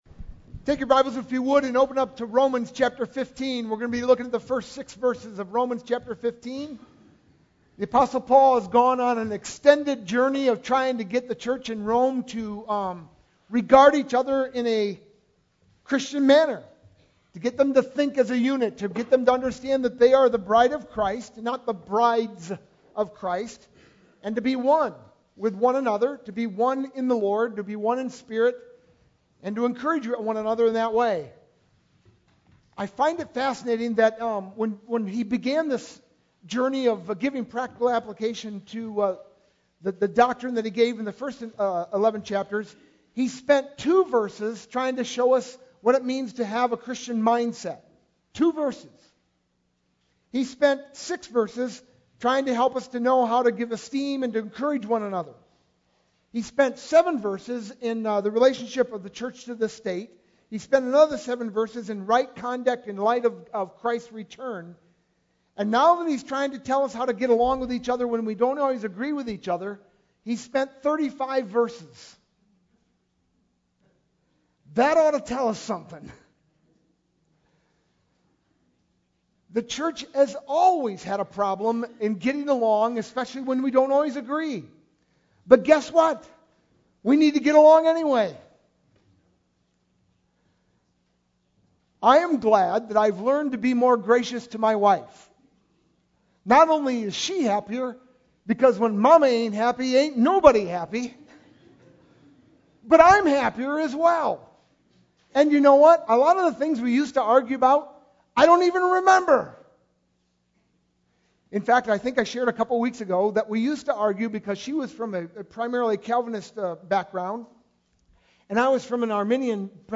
sermon-2-19-12.mp3